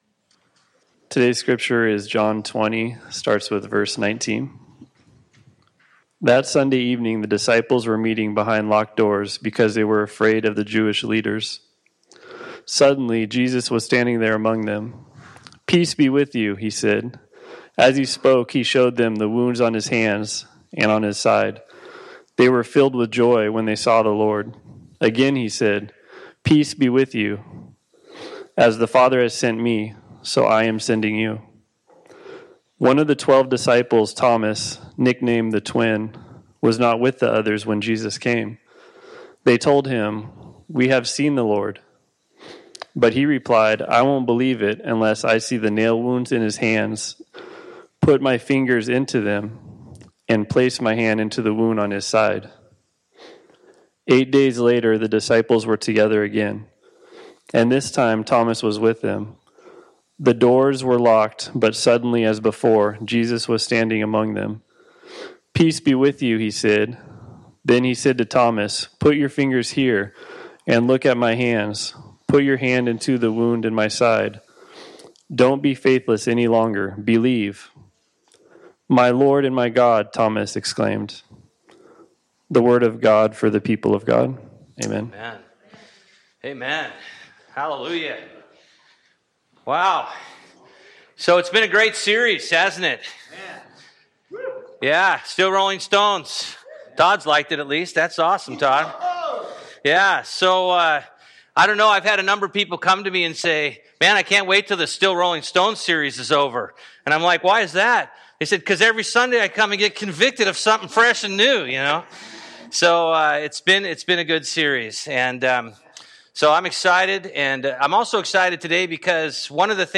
Bible Text: John 20:19-21, 24-28 | Preacher